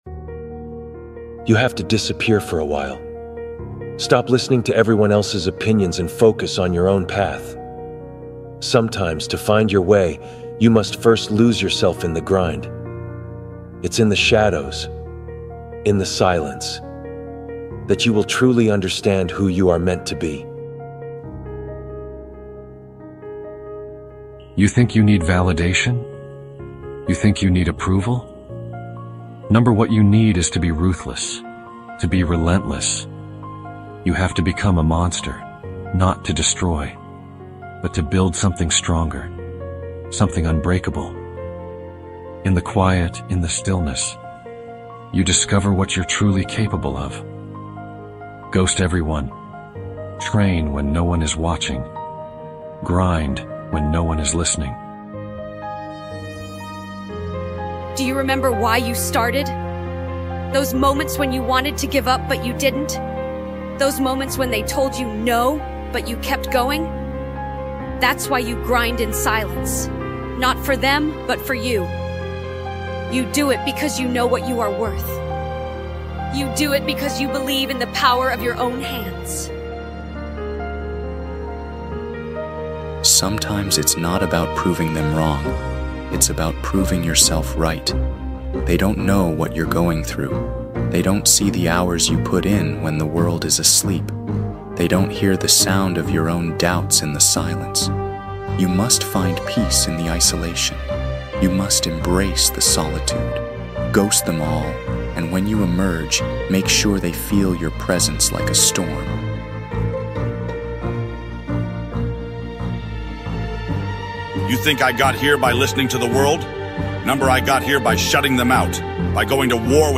Lock In & Focus | Anime Motivational Speech